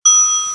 Title=bouton_ding